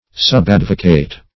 Subadvocate \Sub*ad"vo*cate\, n. An under or subordinate advocate.